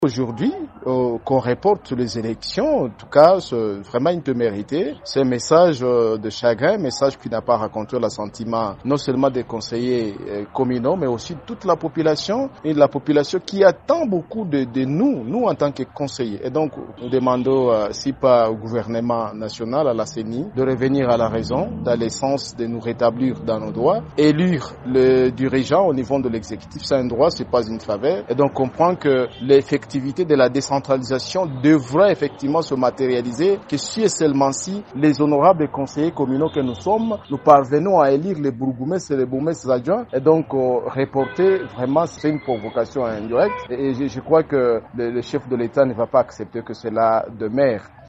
Ecoutons à ce sujet, Daniel Runiga, conseiller communal d’Ibanda.